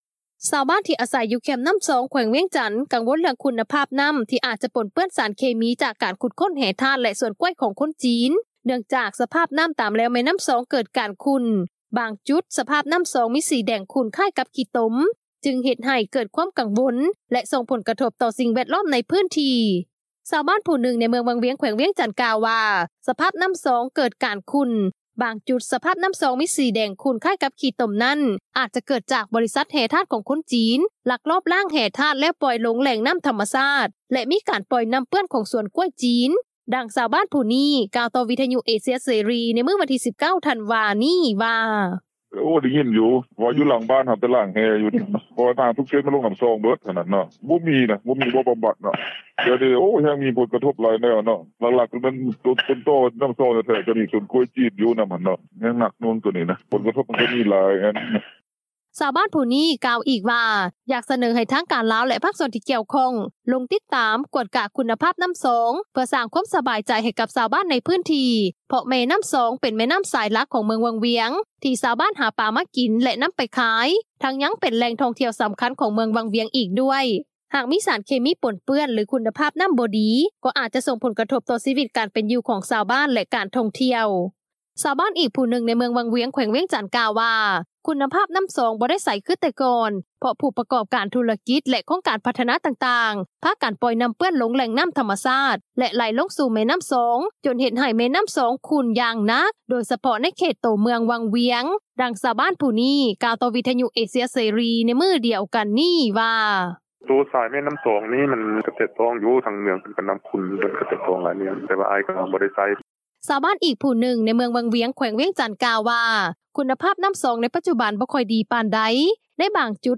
ດັ່ງຊາວບ້ານຜູ້ນີ້ ກ່າວຕໍ່ວິທຍຸເອເຊັຽເສຣີ ໃນມື້ວັນທີ 19 ທັນວາ ນີ້ວ່າ:
ດັ່ງເຈົ້າໜ້າທີ່ ທ່ານນີ້ ກ່າວຕໍ່ວິທຍຸເອເຊັຽເສຣີ ໃນມື້ດຽວກັນນີ້ວ່າ: